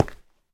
Sound / Minecraft / step / stone4.ogg
stone4.ogg